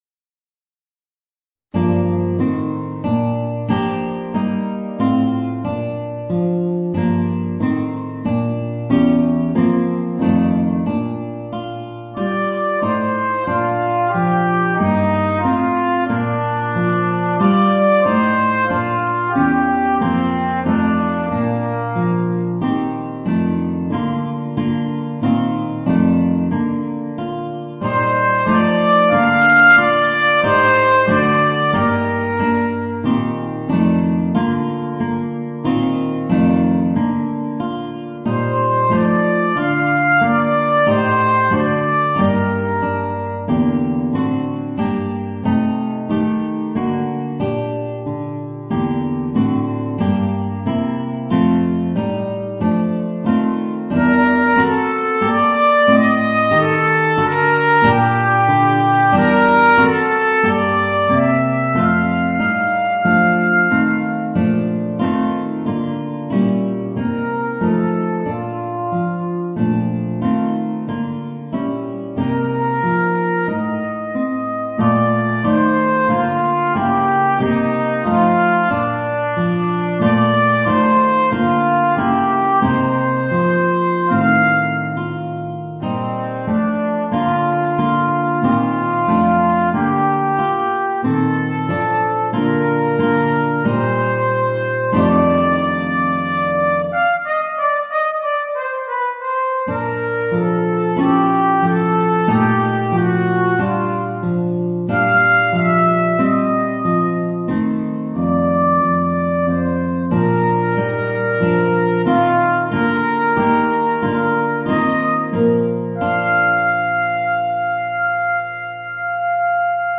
Voicing: Trumpet and Guitar